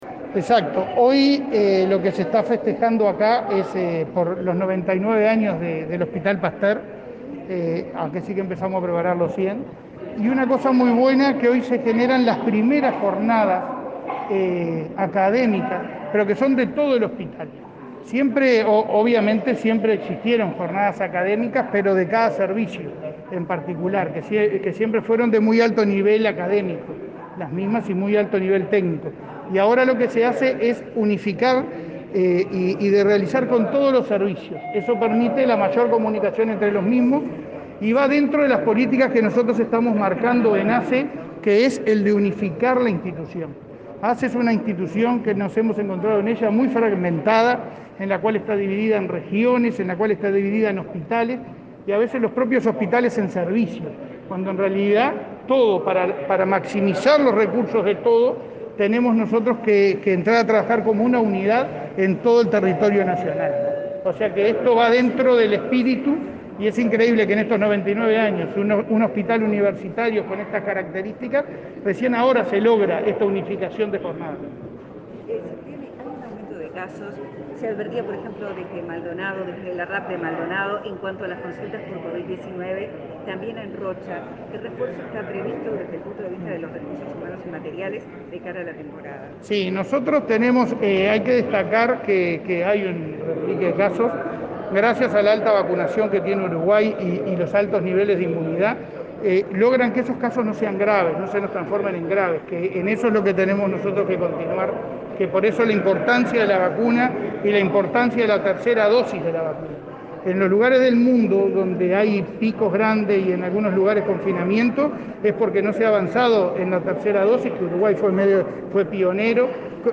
Declaraciones del presidente de ASSE a la prensa
Declaraciones del presidente de ASSE a la prensa 22/11/2021 Compartir Facebook X Copiar enlace WhatsApp LinkedIn El presidente de ASSE, Leonardo Cipriani, participó en la apertura de las Primeras Jornadas Académicas, realizadas este lunes 22 en conmemoración del 99.° aniversario del hospital Pasteur, y, luego, dialogó con la prensa.